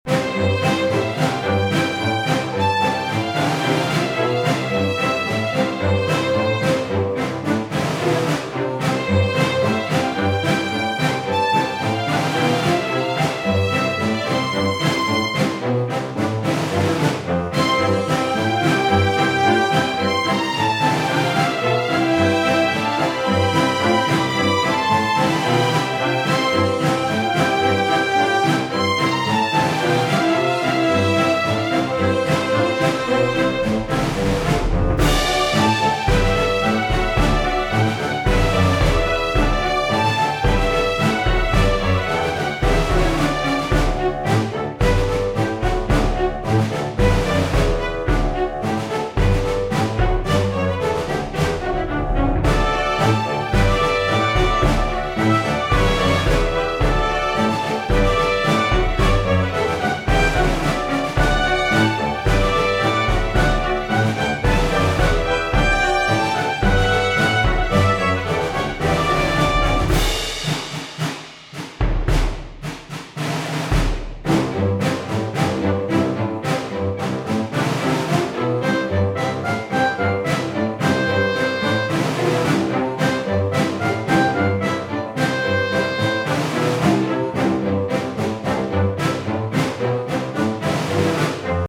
Hey All, Just a quick goofy loopable piece that makes me feel like I am watch a marching circus in some studio ghibli movie.